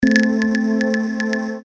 • Качество: 320, Stereo
спокойные
без слов
эхо
Похоже на звук сонара